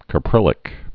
(kə-prĭlĭk, kă-)